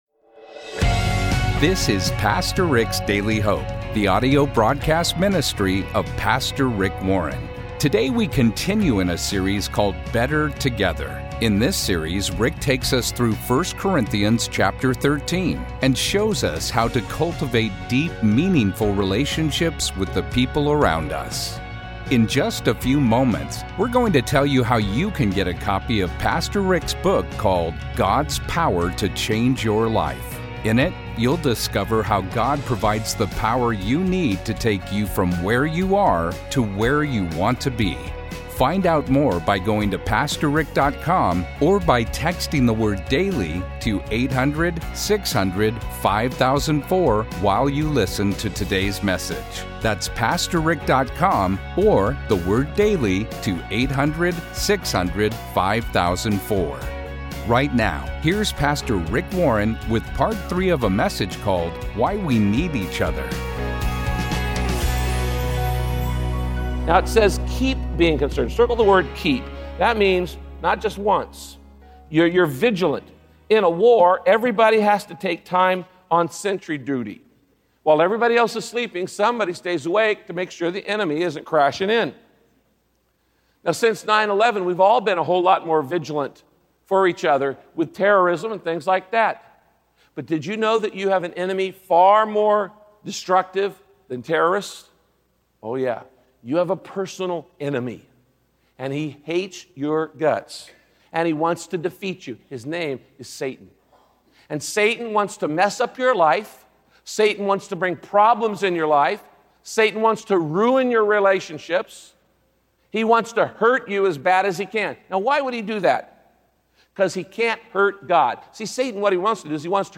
My Sentiment & Notes Why We Need Each Other - Part 3 Podcast: Pastor Rick's Daily Hope Published On: Tue Jul 25 2023 Description: Tragedy and heartache are inevitable, but you can prepare for them by building a network of supporters and friends. In this message, Pastor Rick teaches the importance of building such a safety net—and that the time for doing it is now.